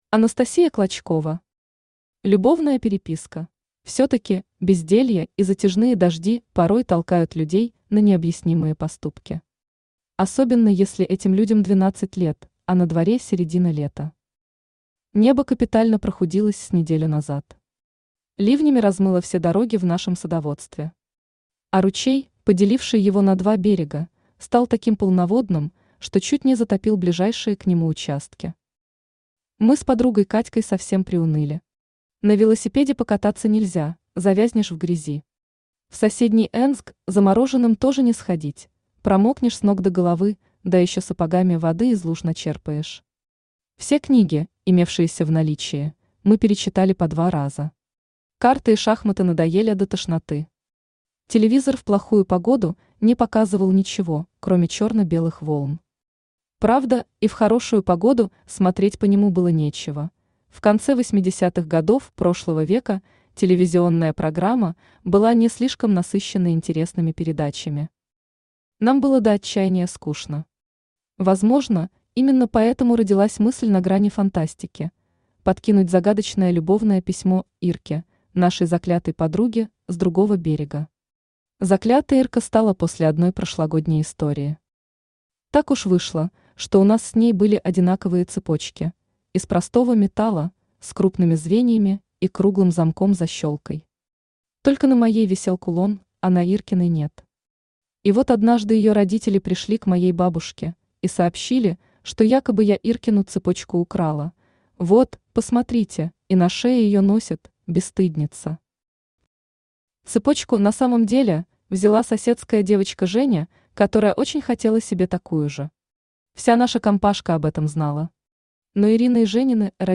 Аудиокнига Любовная переписка | Библиотека аудиокниг
Aудиокнига Любовная переписка Автор Анастасия Клочкова Читает аудиокнигу Авточтец ЛитРес.